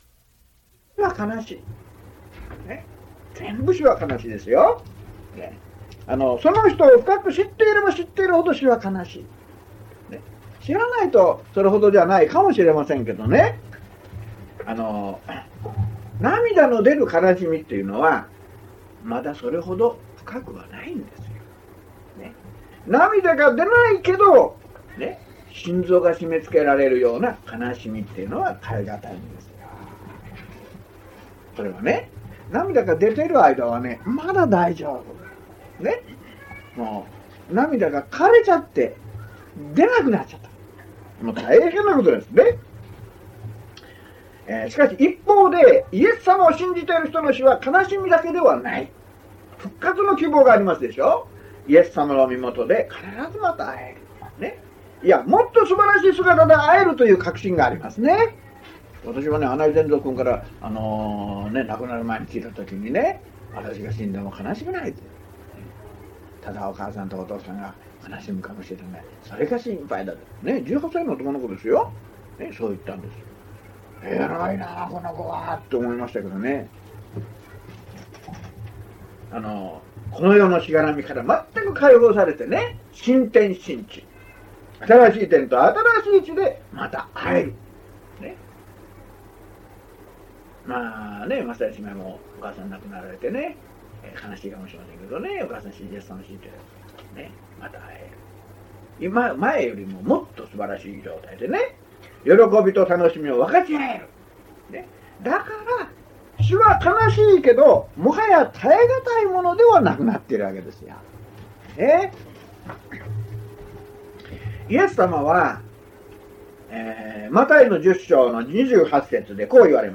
（途中から始まります）